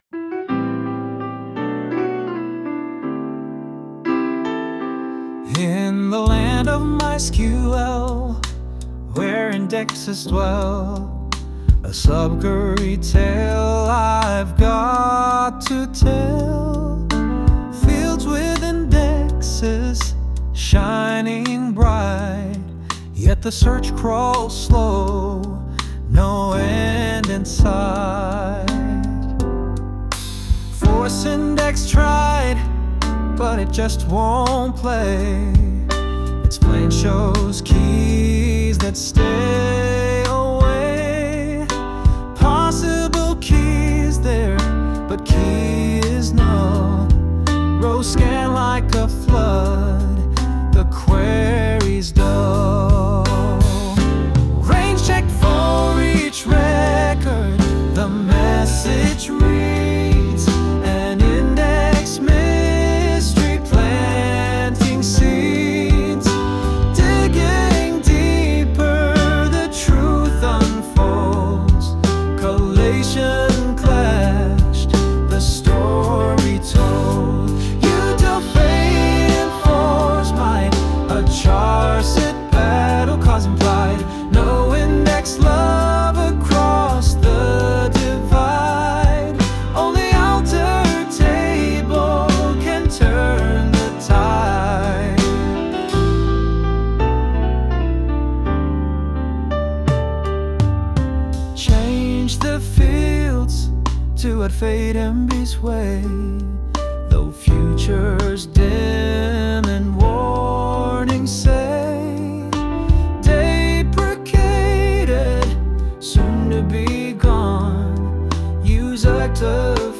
Sing this blog article